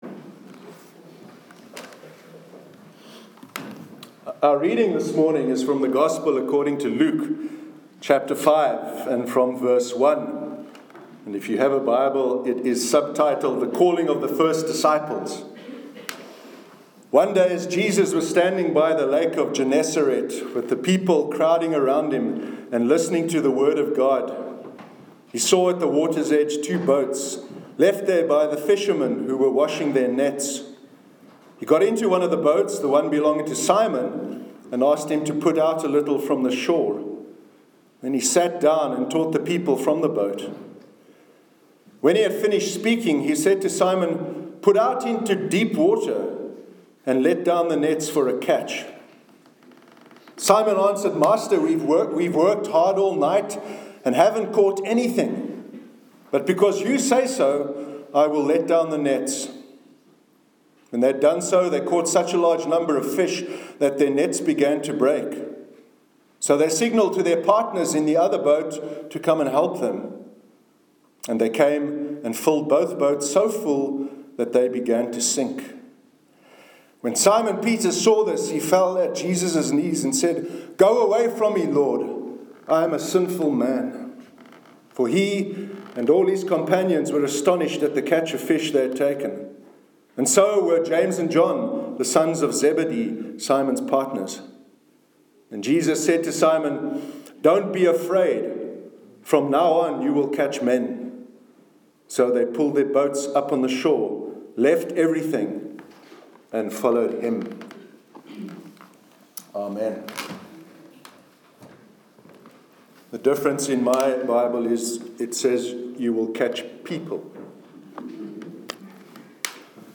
Sermon on Jesus Calling His First Disciples- 19th November 2017